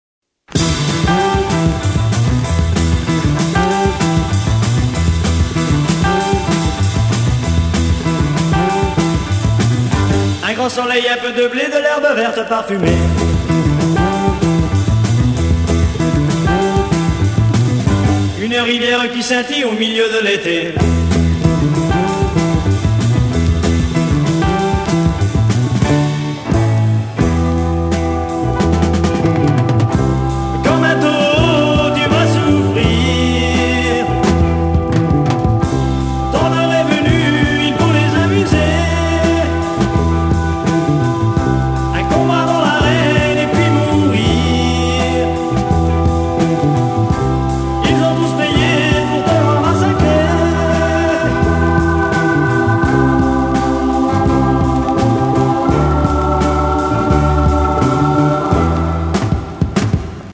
Pop progressif Unique 45t retour à l'accueil